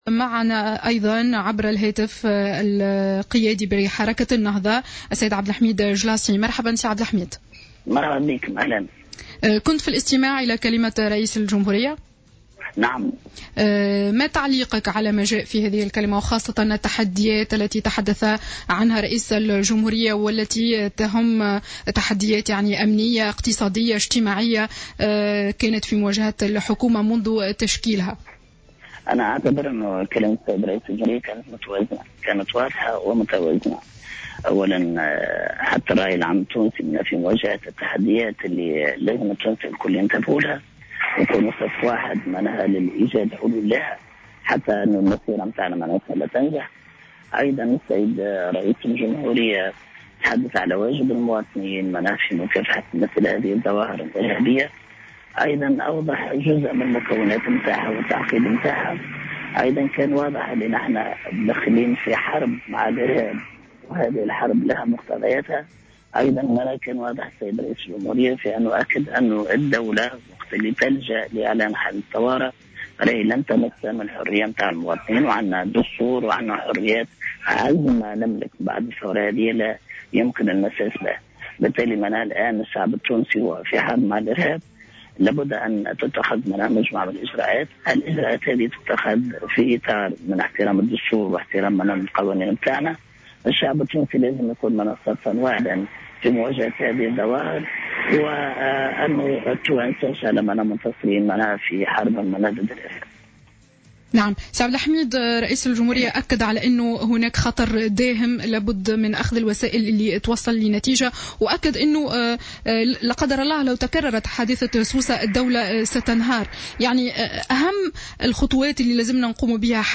اعتبر عبد الحميد الجلاصي القيادي في حركة النهضة في تصريح لجوهرة أف أم اليوم السبت 04 جويلية 2015 في تعليقه على قرار إعلان حالة الطوارئ أننا فعلا في حرب مع الإرهاب و أن للحرب مقتضياتها مؤكدا أن الدولة لن تمس من حرية المواطنين عند لجوئها إلى حالة الطوارئ.